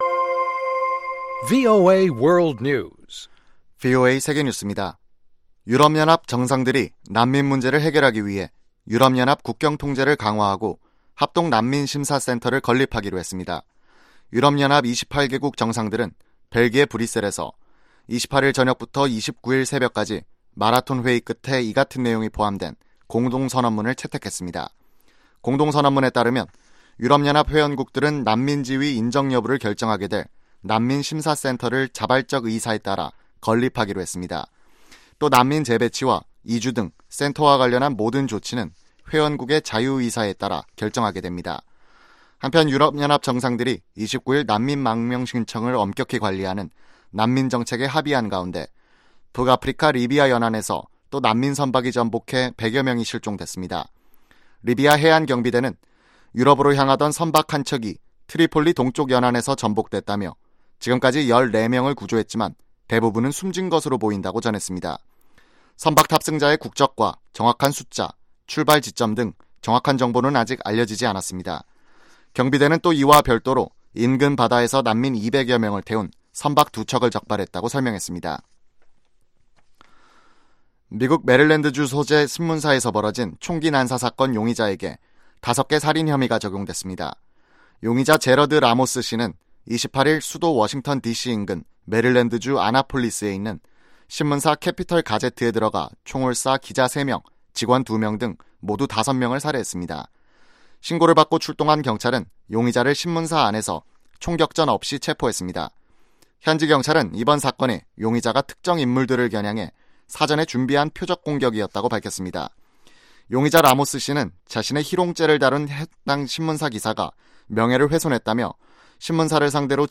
VOA 한국어 아침 뉴스 프로그램 '워싱턴 뉴스 광장' 2018년 6월 30일 방송입니다. 미 국무부가 16년 연속 북한을 최악의 인신매매 국가로 지정했습니다. 미 하원이 한국 전쟁 참전 미군 유해 송환을 위해 100만 달러의 예산을 배정했습니다.